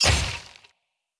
claw.wav